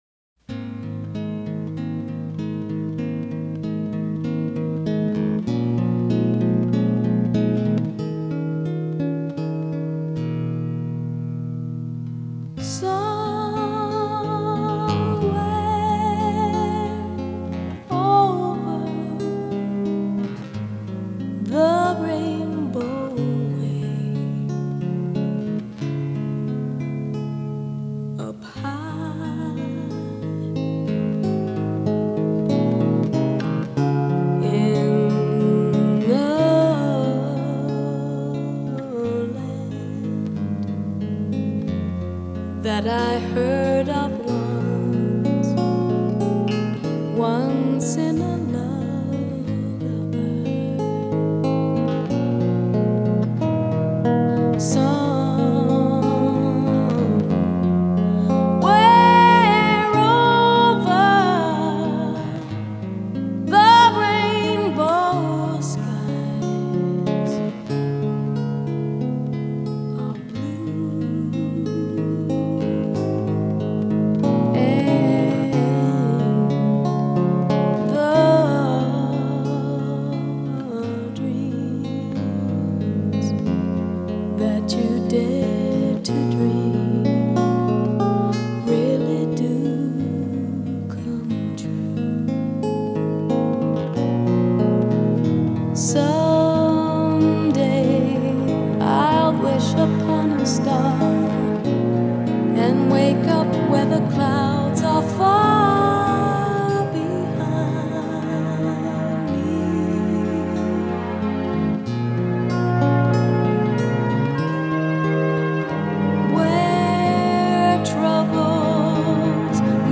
bossa nova慵懒的节奏令我们乘着歌声的翅膀走向彩虹另一端。